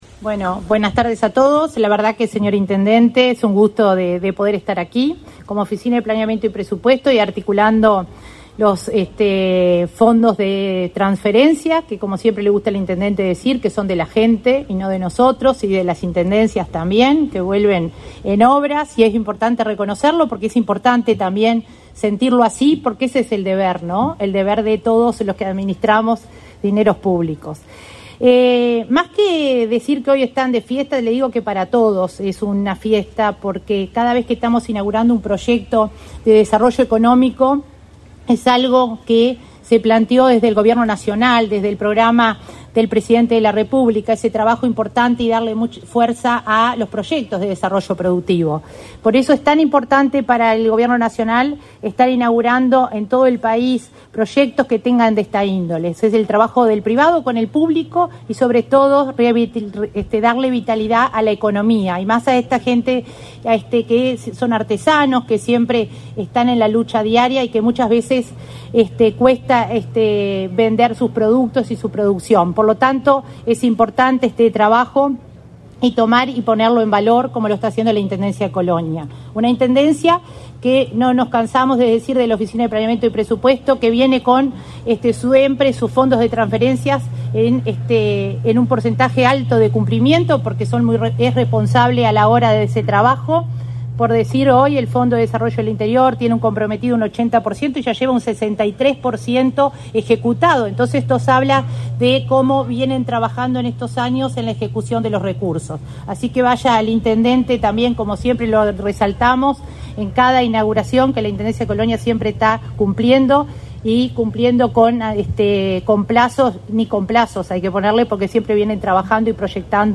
Palabras de la coordinadora de Descentralización y Cohesión de la OPP, María de Lima
La Oficina de Planeamiento y Presupuesto (OPP) y la Intendencia de Colonia inauguraron, este 10 de mayo, el Mercado Artesanal de la capital departamental, obra ejecutada a través del Fondo de Desarrollo del Interior. En el evento participó la coordinadora de Descentralización y Cohesión de la OPP, María de Lima.